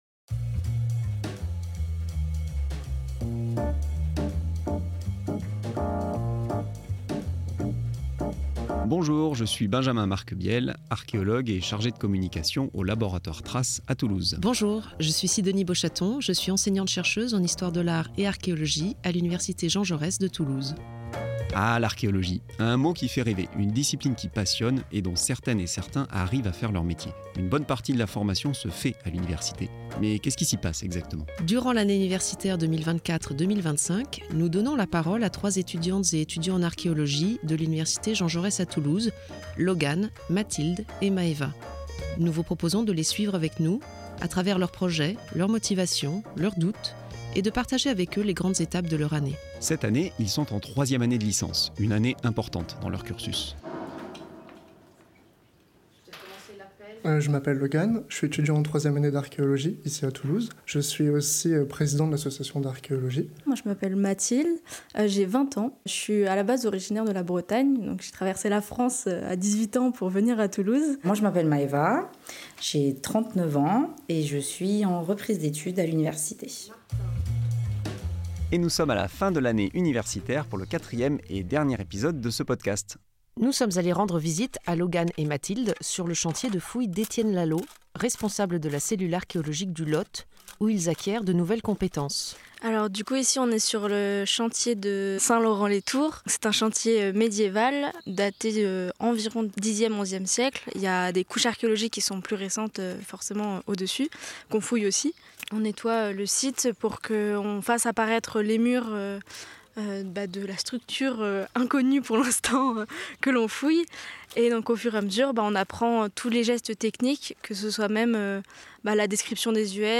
sur le campus de l’UT2J
sur le chantier de fouille de Saint-Laurent-les-Tours, dans le Lot.